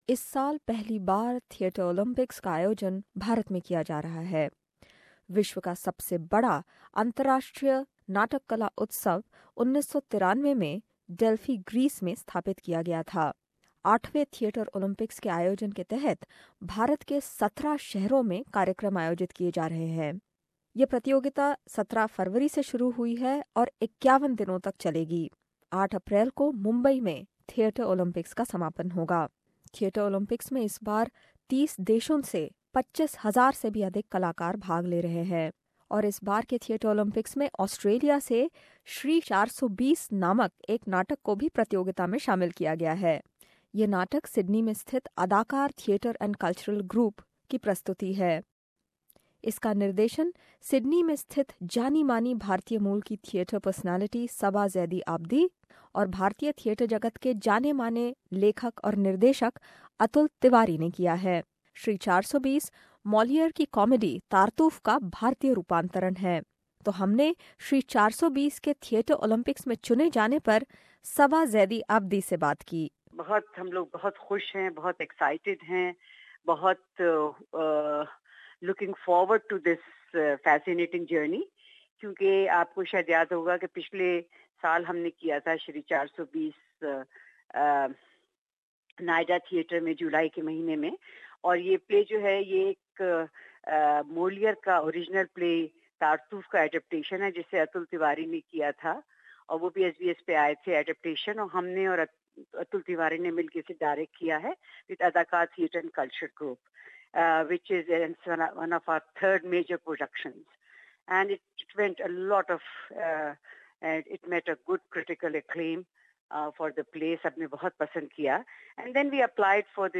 We spoke to the team of 'Shri 420' before they left to take part in world's biggest international theatre festival. Tune in for this report...